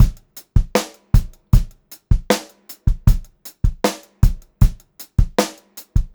78STRBEAT1-R.wav